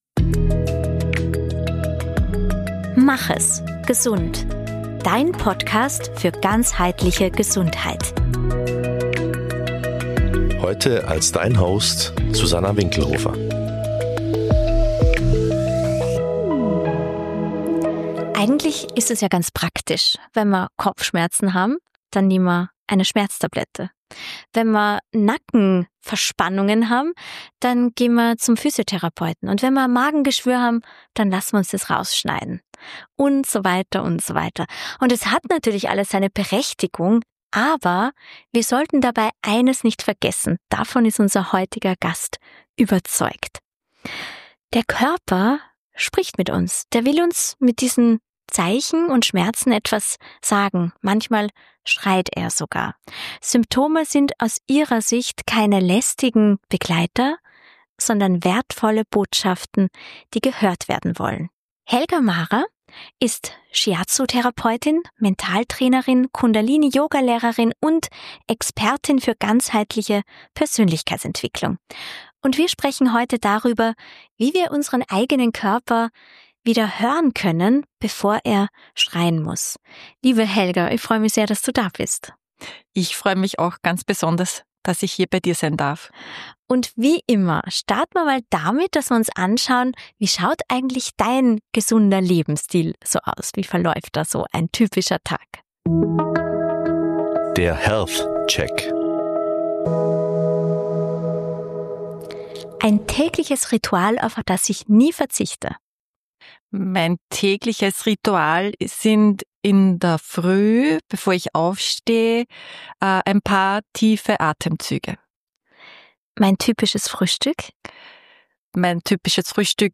Ein Gespräch über die Sprache der Symptome.